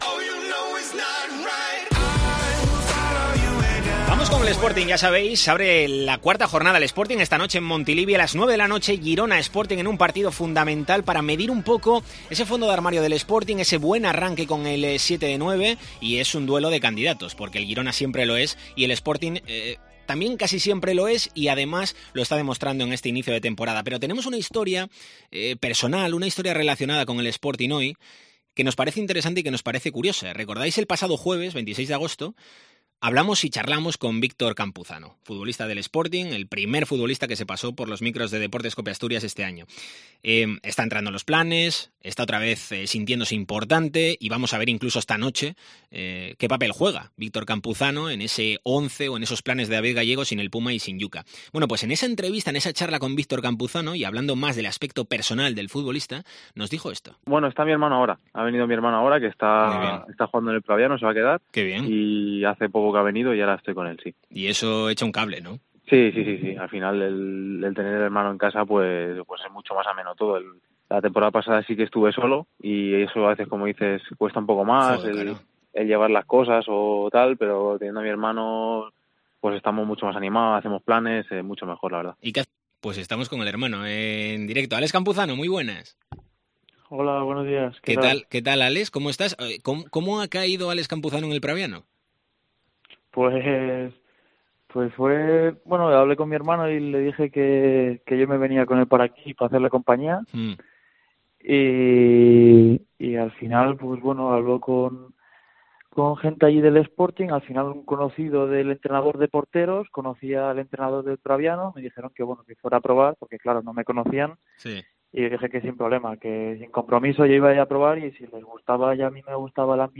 Deportes COPE Asturias Entrevista